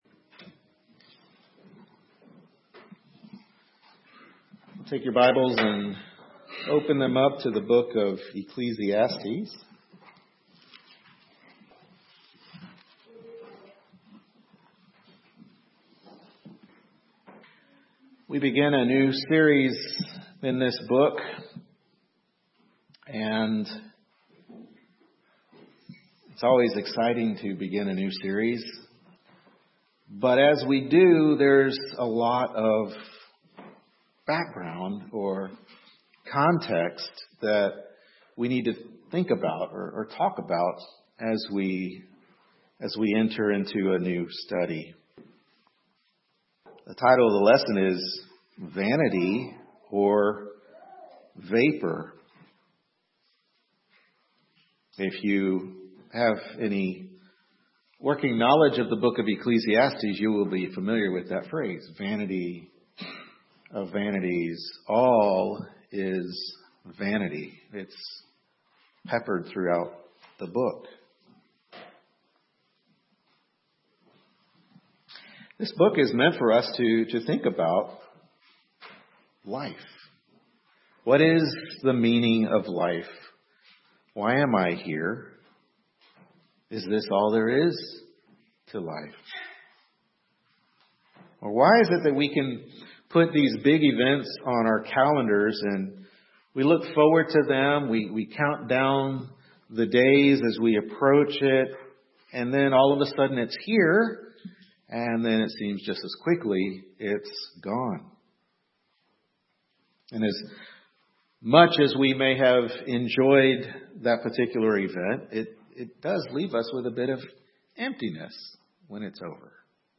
Ecclesiastes 1:1-3 Service Type: Morning Worship Service Ecclesiastes 1:1-3 Vanity or Vapor? Topics: Brevity of Life , Vanity , Vapor.